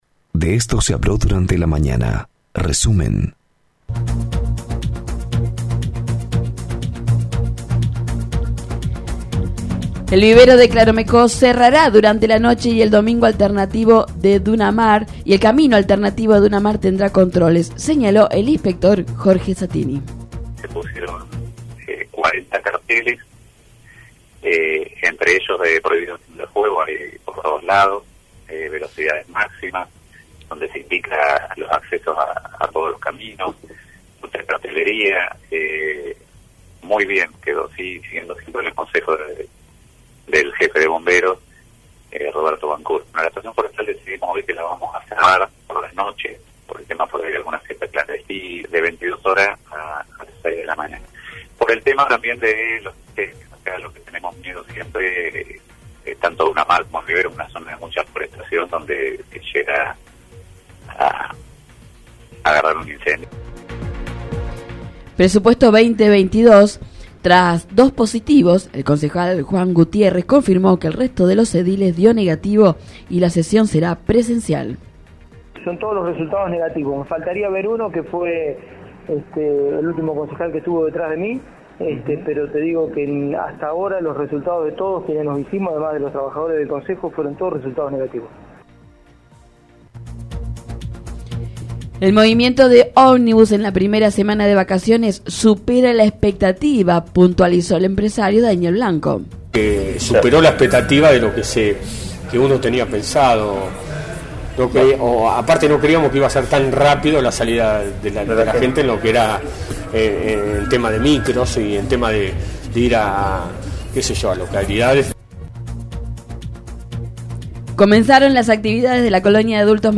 Escuchá los principales temas noticiosos de la mañana en nuestro resumen de Radio 3 95.7.